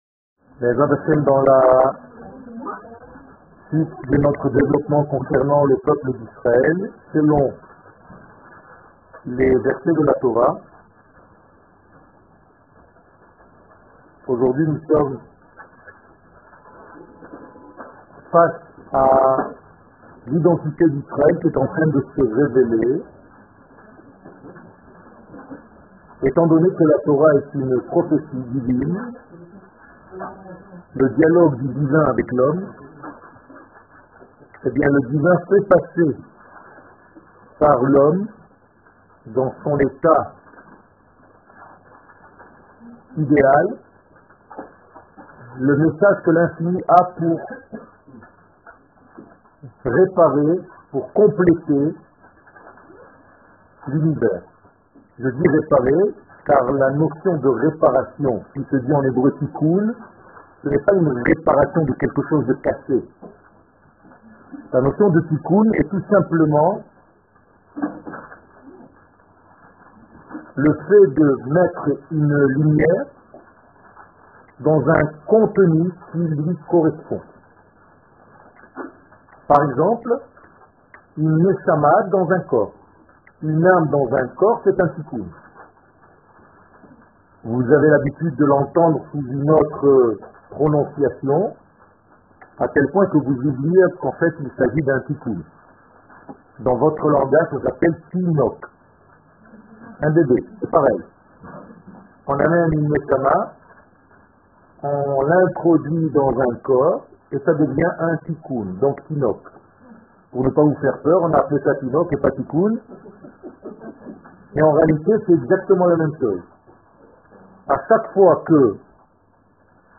Le peuple d'Israel #2 Eretz Israel שיעור מ 07 נובמבר 2017 59MIN הורדה בקובץ אודיו MP3 (54.48 Mo) הורדה בקובץ אודיו M4A (7.51 Mo) TAGS : Etude sur la Gueoula Emouna Torah et identite d'Israel שיעורים קצרים